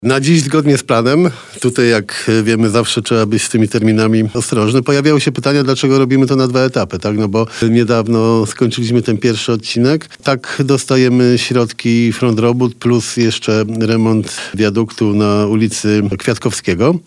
Prace rozpoczęły się w czerwcu i przebiegają zgodnie z harmonogramem, o czym mówił na naszej antenie prezydent miasta.